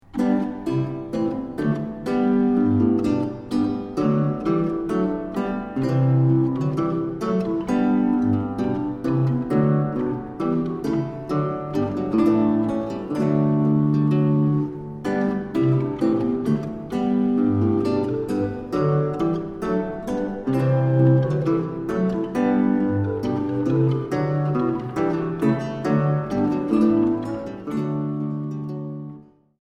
Italienische Lautenmusik des Barock
Laute
Orgelpositiv